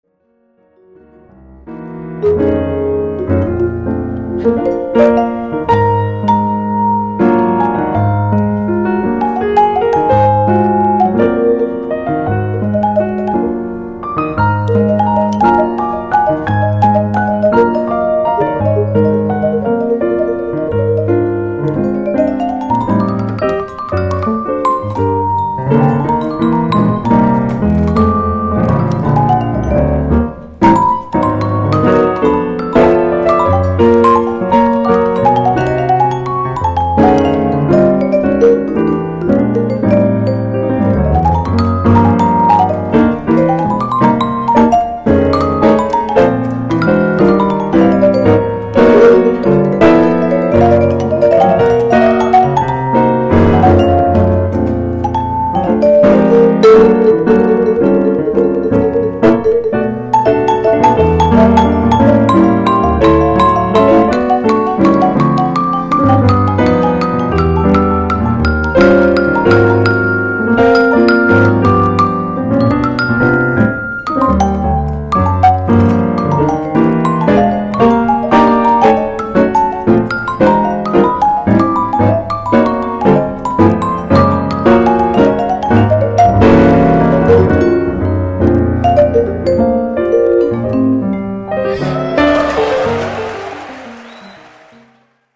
The solo chorus: